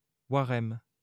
Waremme (French: [waʁɛm]